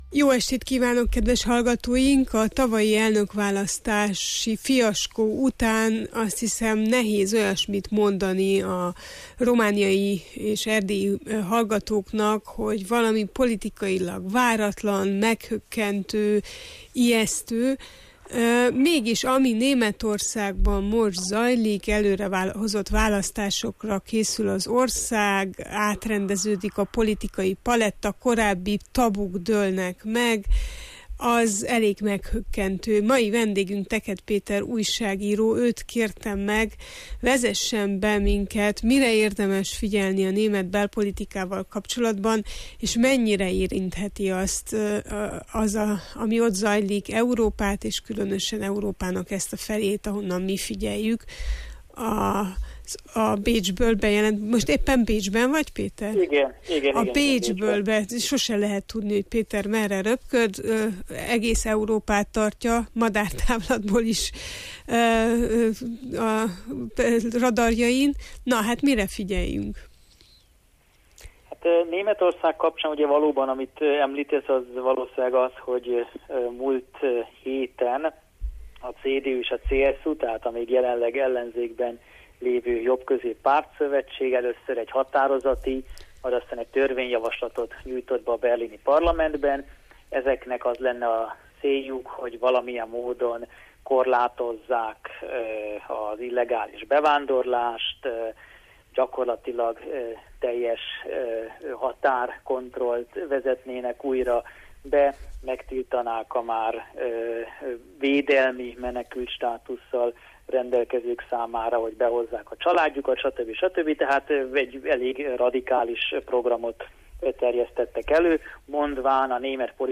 újságíróval arról beszélgettünk